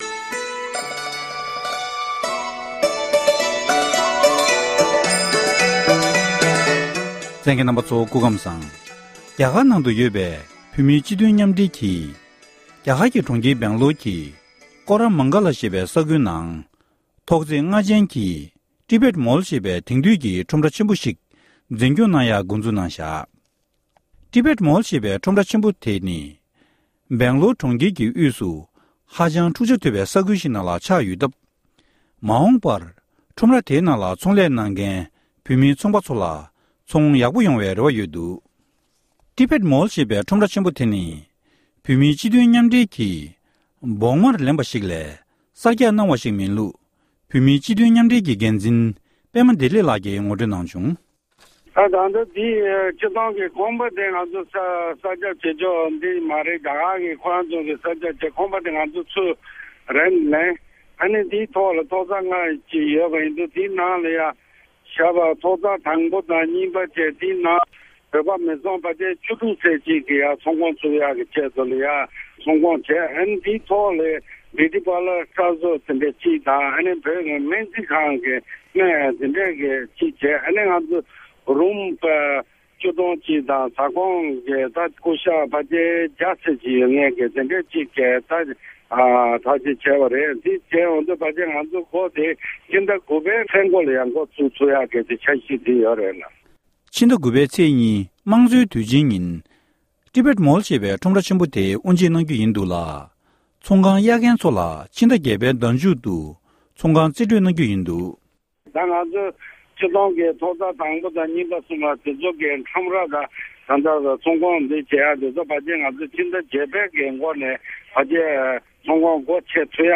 གནས་འདྲི་ཞུས་ཡོད༎